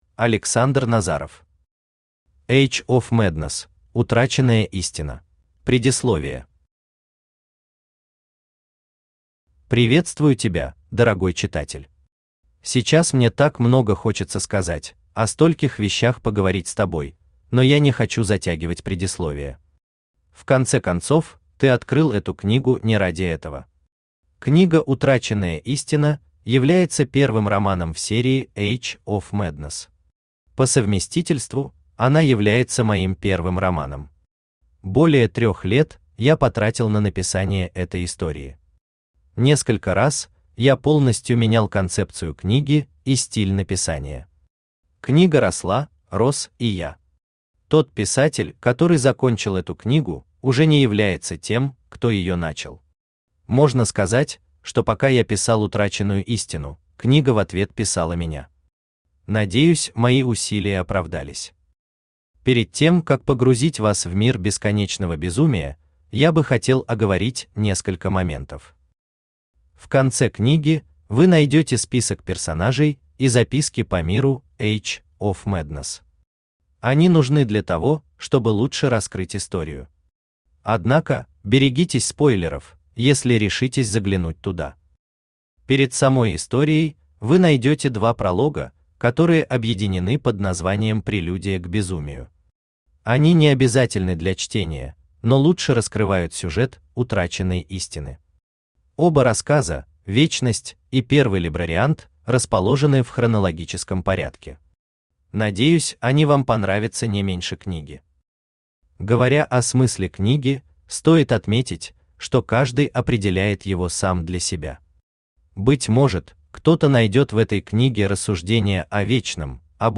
Aудиокнига Age of Madness: Утраченная истина Автор Александр Назаров Читает аудиокнигу Авточтец ЛитРес.